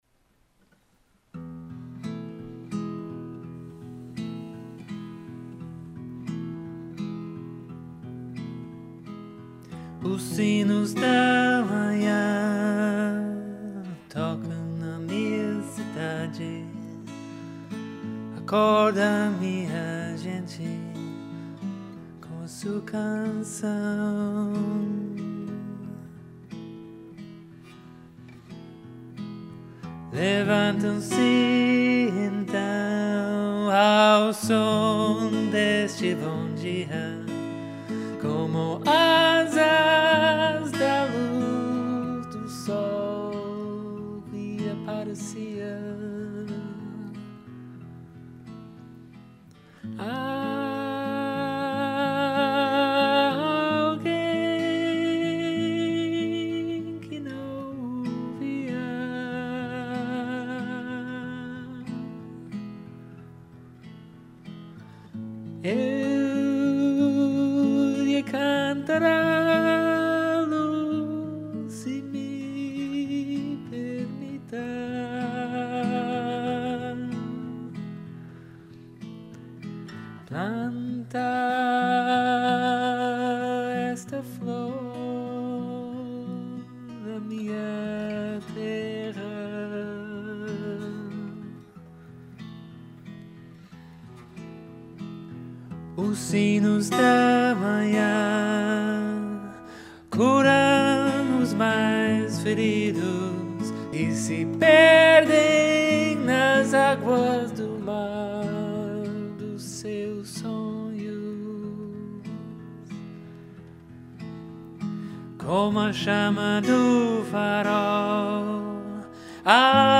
N.B. corrections to grammar took place after the fact: I will never have a voice this clear again, so this recording remains, with so-so Portuguese accompanied by a certain lightness of spirit.